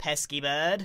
Pesky Bird